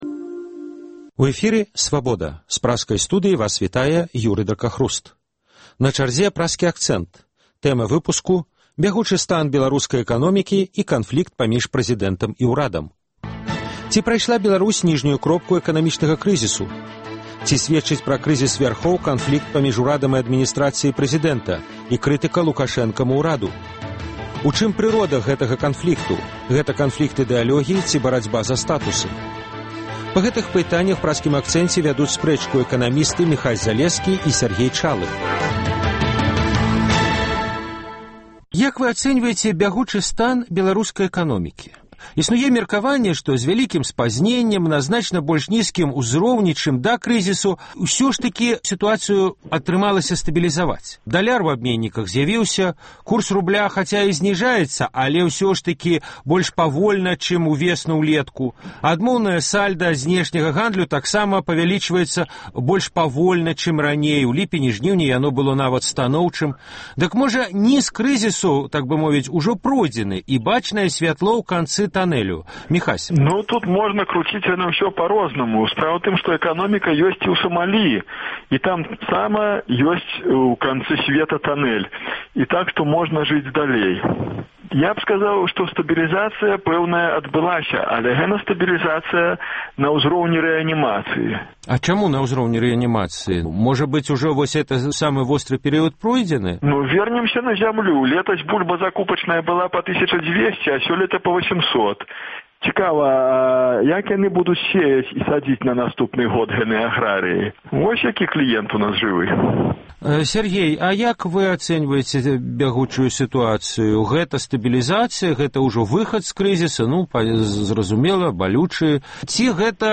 Па гэтых пытаньнях вядуць спрэчку эканамісты